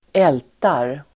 Uttal: [²'el:tar]